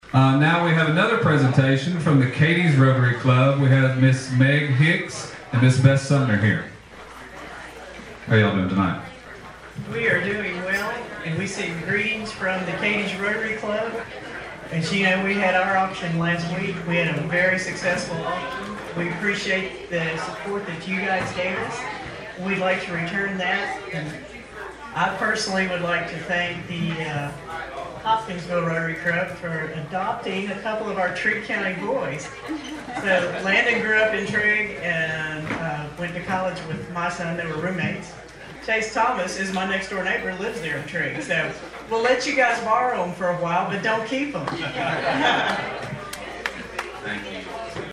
Always looking to raise “one more dollar than Cadiz,” Wednesday night’s efforts at the 75th Annual Hopkinsville Rotary Auction inside the War Memorial Building helped the club move well past $200,000 in fundraising.